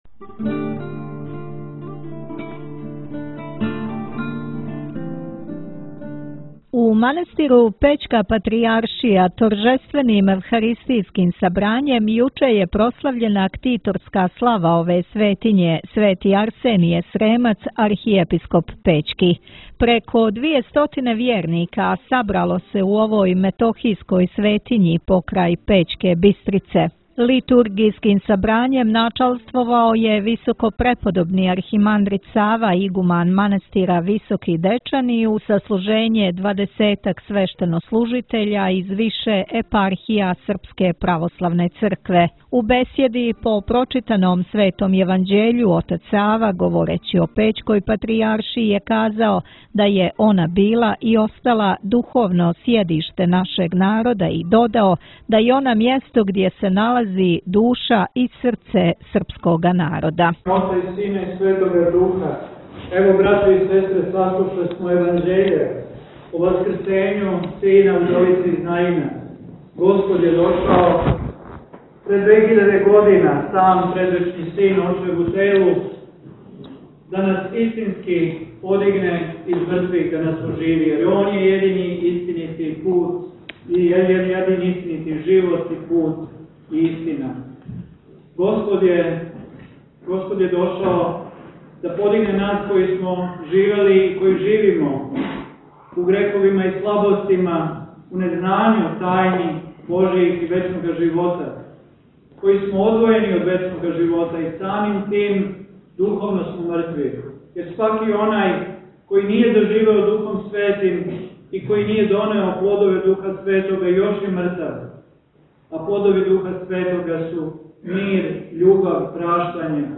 Празничну литургију служио је игуман манастира Високи Дечани, архимандрит Сава (Јањић) уз саслужење двадесетак свештеника из епархија Српске православне цркве док су на литургији појањем одговарали ученици Призренске богословијe.